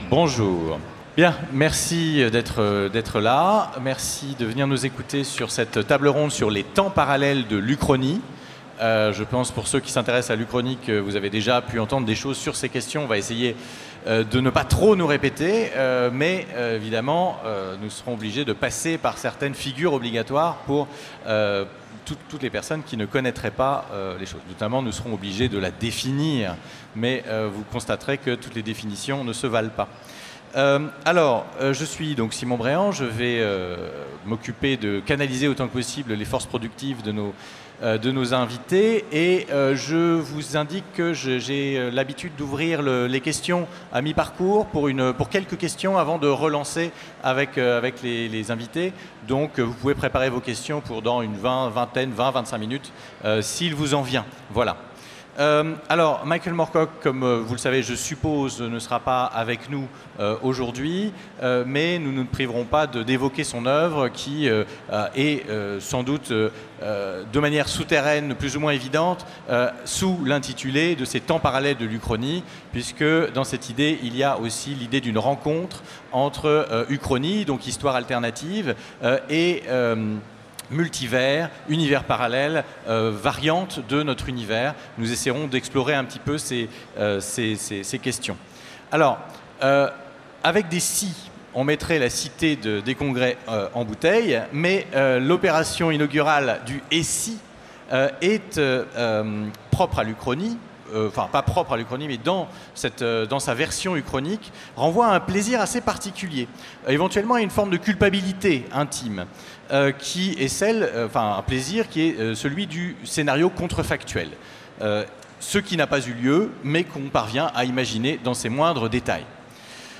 Utopiales 2017 : Conférence Les temps parallèles de l'uchronie